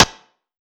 SNARE BLOCK2.wav